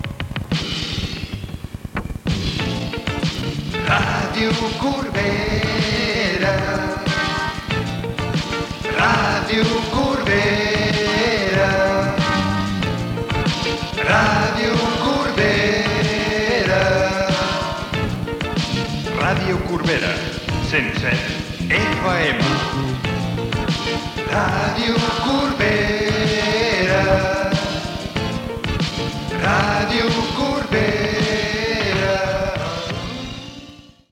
0da3464cb1141e1045e68f37dee119c2abe1497a.mp3 Títol Ràdio Corbera Emissora Ràdio Corbera Titularitat Pública municipal Descripció Indicatiu de l'emissora.